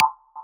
click-short.wav